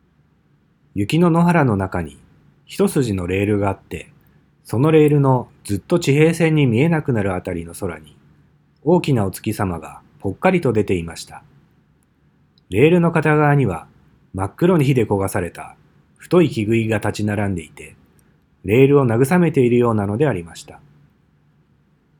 朗読のサンプル
Mac_Dialog.wav